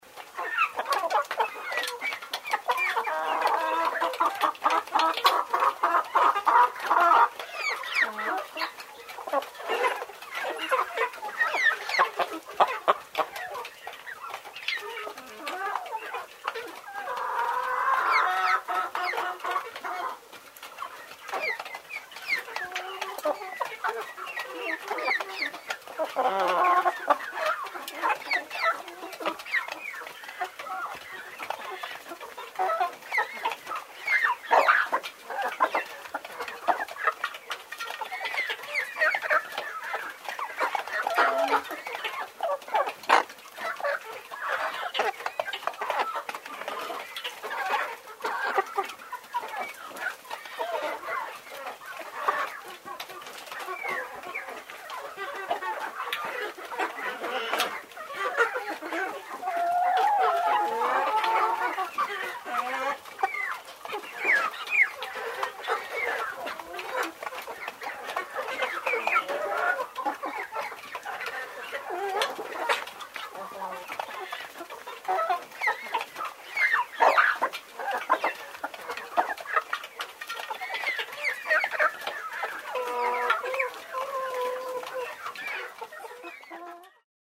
Звуки куриц, петухов
На этой странице собраны разнообразные звуки куриц и петухов: от утреннего крика до квохтания наседки.